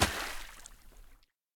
shallow-water-02.ogg